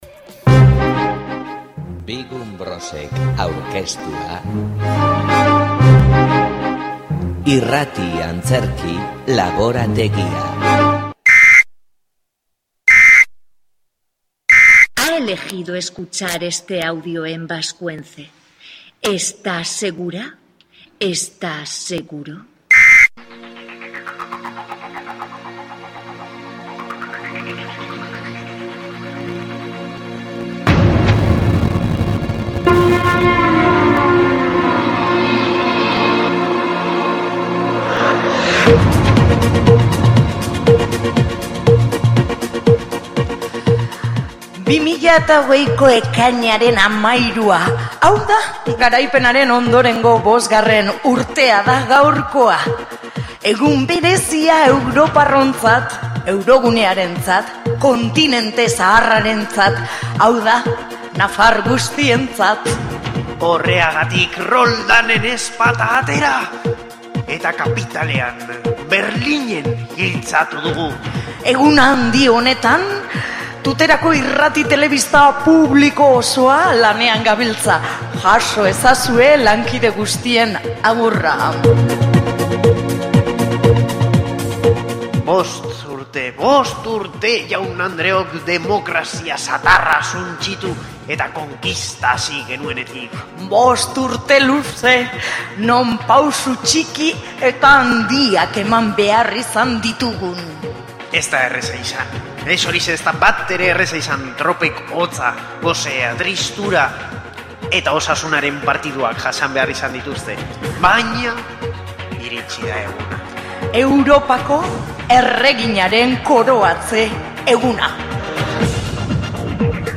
Bozketa, isiltasunean, eta gero txalo zaparrada.
2gunBros Irrati Antzerki laborategian azken esperimentua izan dugu entzungai.
Yolanda I.a Europako erregina izendatzeko egunaren gaineko zuzeneko irrati saioa.